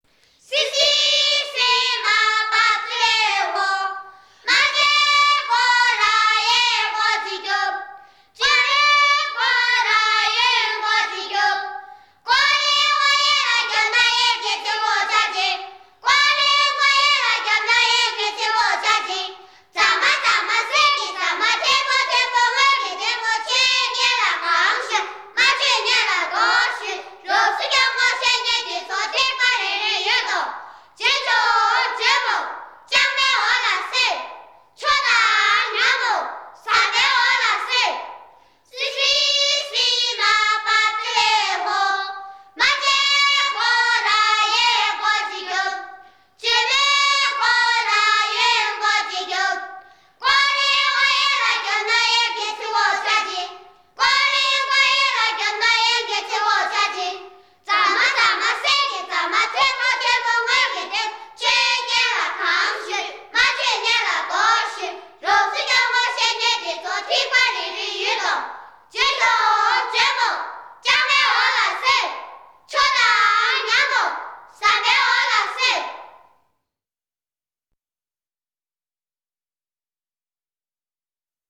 ☆實地收錄山歌、牧歌、兒歌、酒歌..等，讓您親身體會藏族風味獨具的生活面貌。